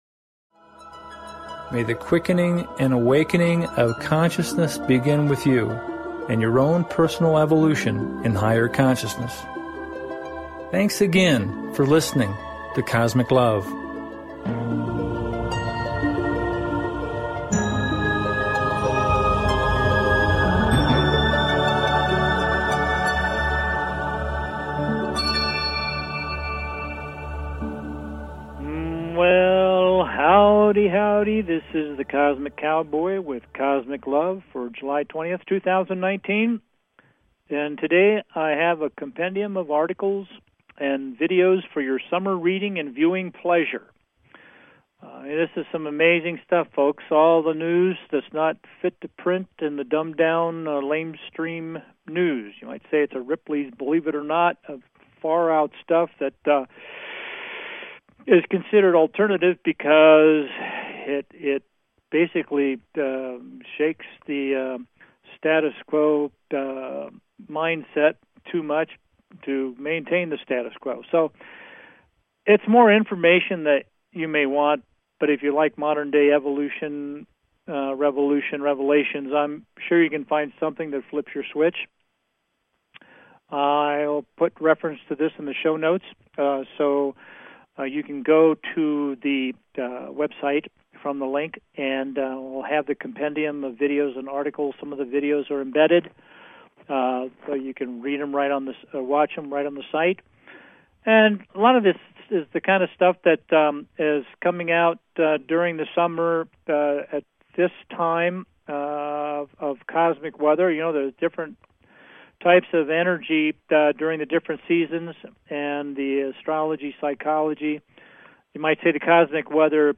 Cosmic LOVE Talk Show